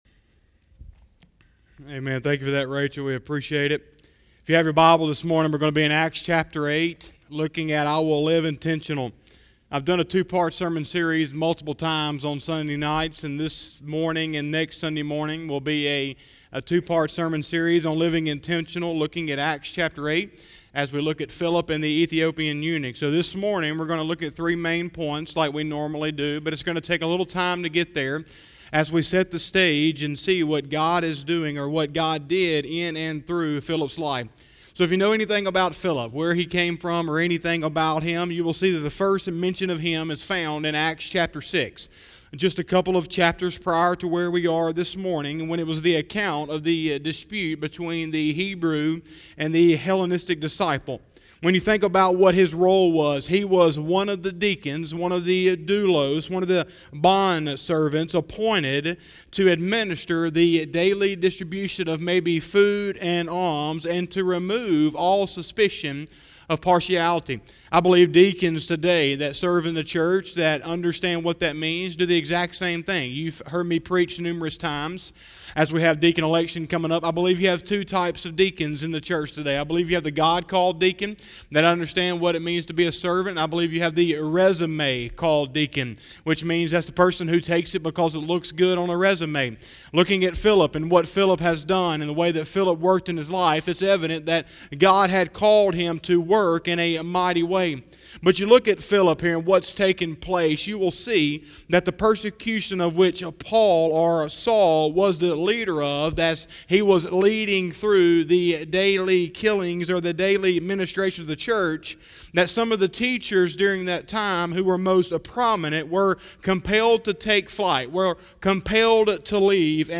07/12/2020 – Sunday Morning Service